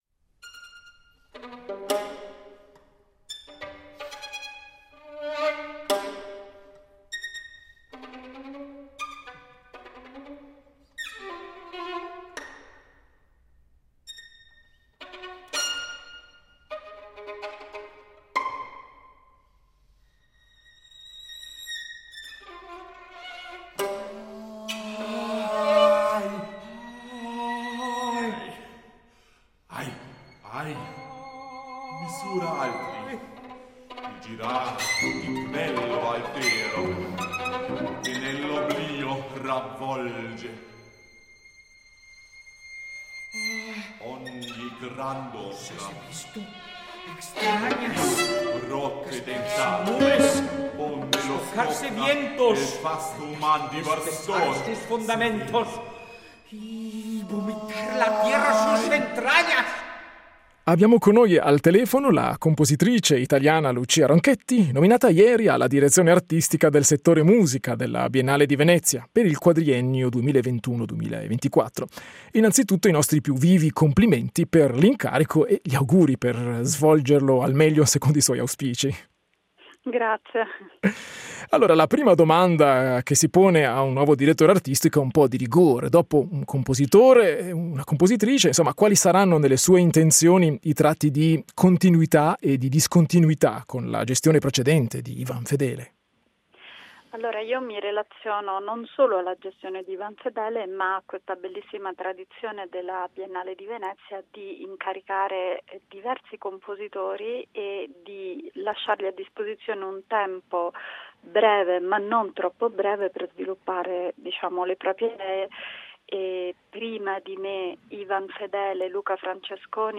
a colloquio